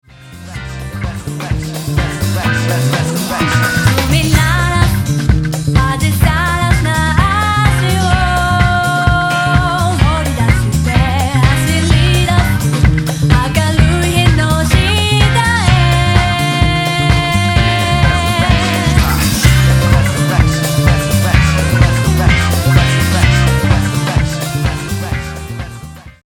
playPIANO & KEYBOARD